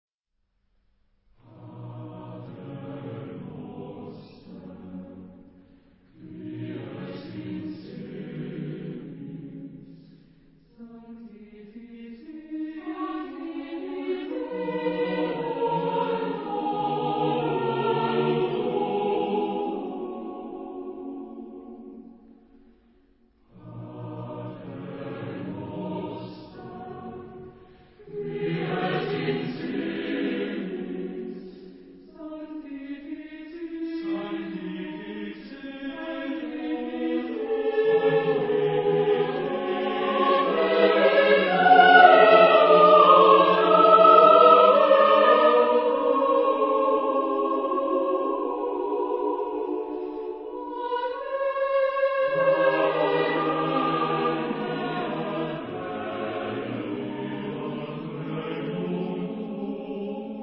SATB (4 gemischter Chor Stimmen) ; Partitur.
geistlich. zeitgenössisch. Chor.